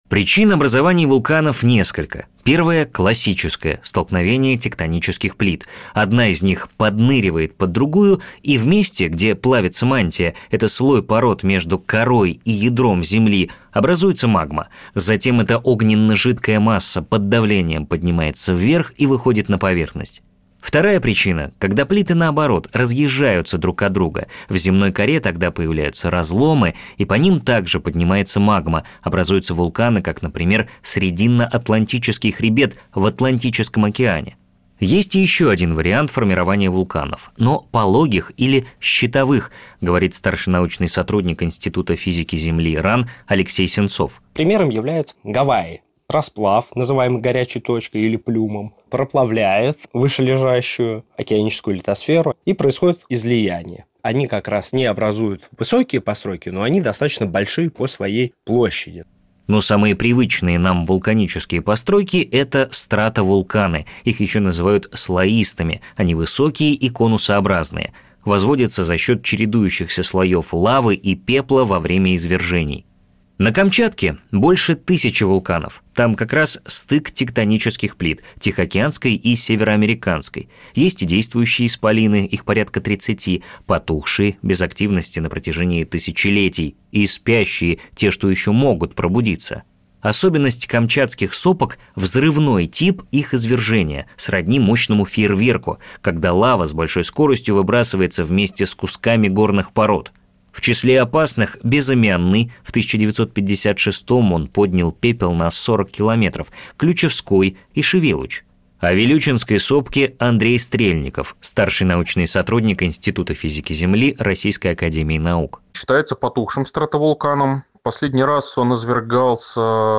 Запись эфира на радио «Звезда»